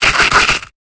Cri d'Écrapince dans Pokémon Épée et Bouclier.